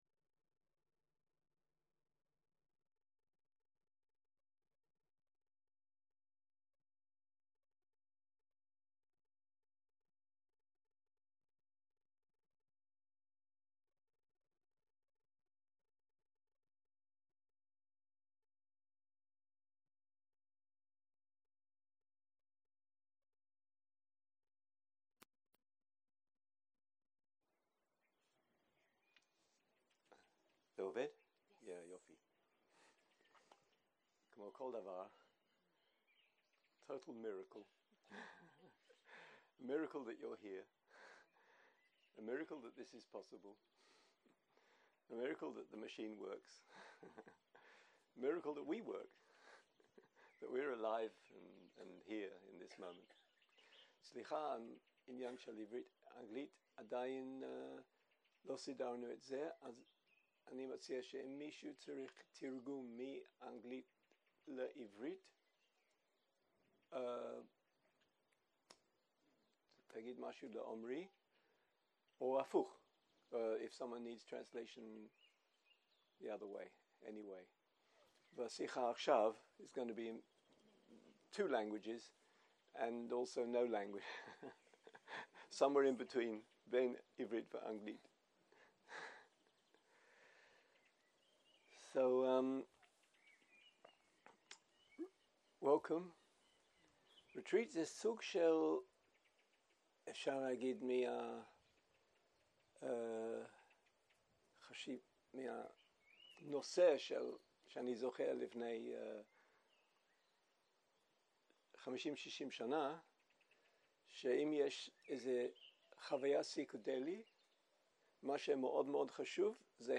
0:00 0:00 סוג ההקלטה: Dharma type: Opening talk שפת ההקלטה: Dharma talk language: Hebrew